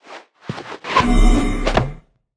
Sfx_Anim_Super_Witch.wav